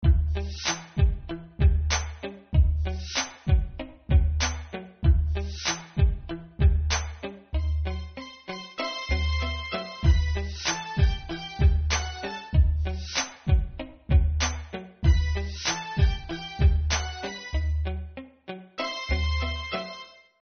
Минусовки (Hip-Hop)
Агрессивные:
7. (инструментальный); темп (105); продолжительность (3:28)